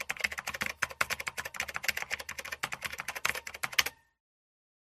Fast Typing
Fast Typing is a free ui/ux sound effect available for download in MP3 format.
102_fast_typing.mp3